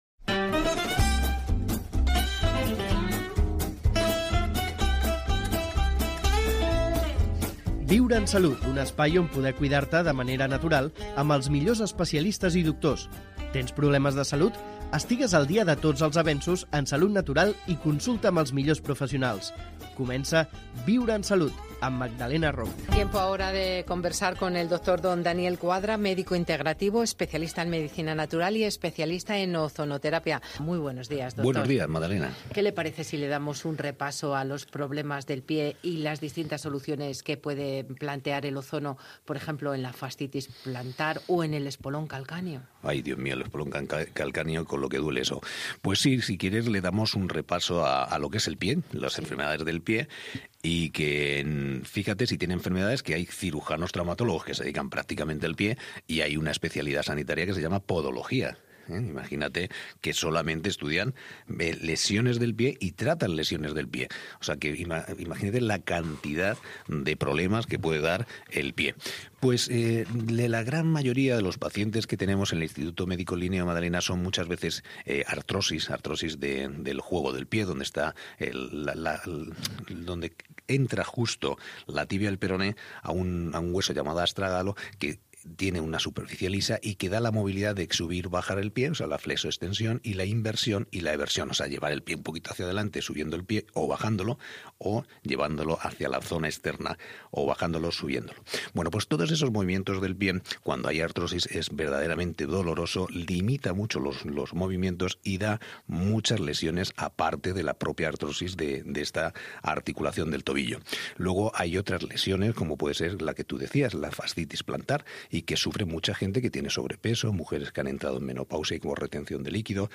Viure en Salut, un magazín de medicina natural, teràpies alternatives i qualitat de vida.